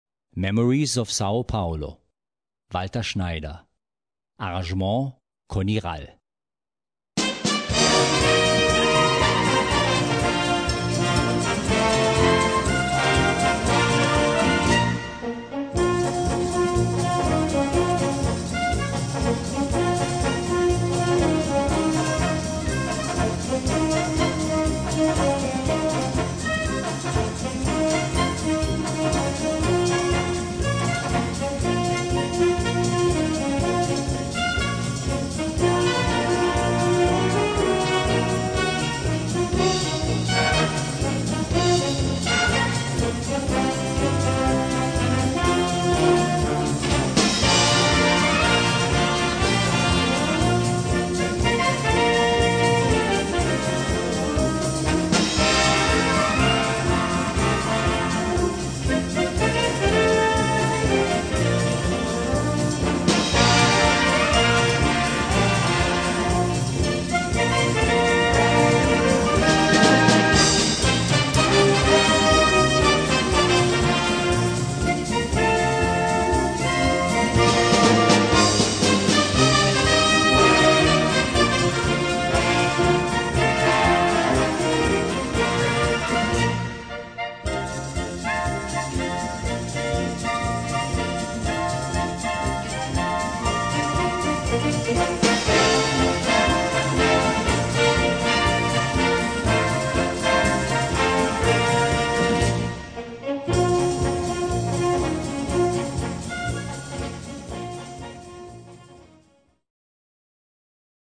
Gattung: Moderne Unterhaltungsmusik
Besetzung: Blasorchester